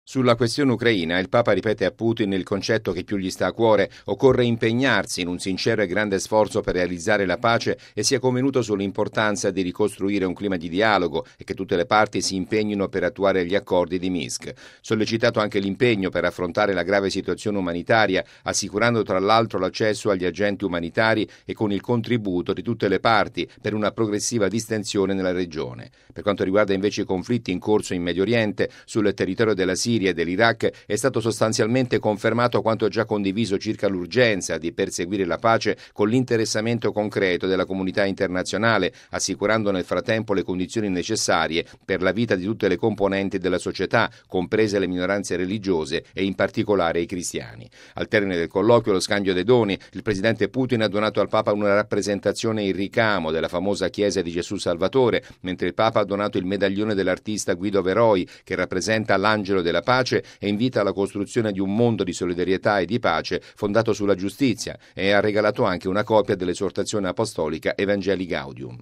Bollettino Radiogiornale del 11/06/2015